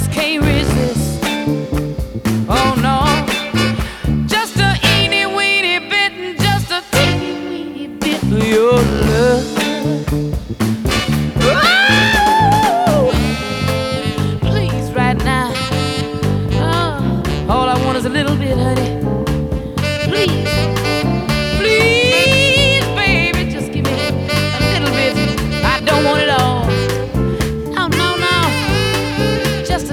# Blues